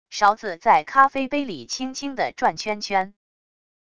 勺子在咖啡杯里轻轻地转圈圈wav音频